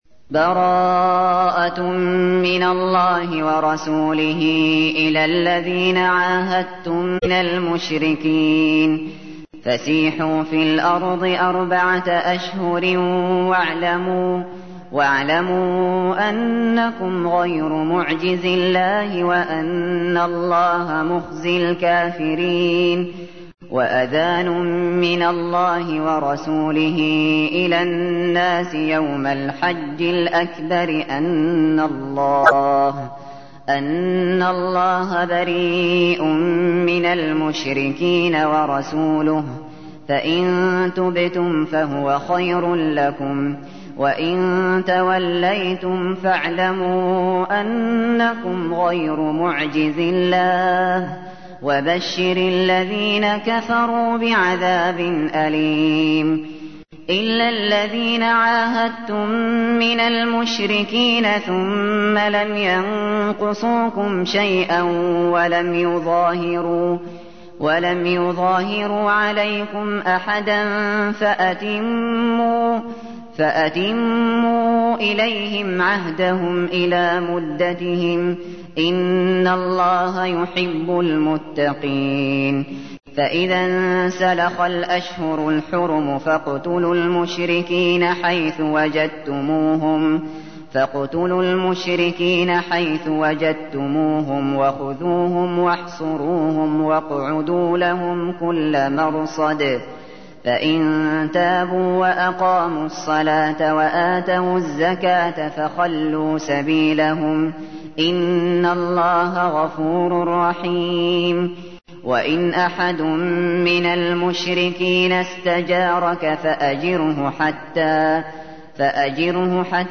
تحميل : 9. سورة التوبة / القارئ الشاطري / القرآن الكريم / موقع يا حسين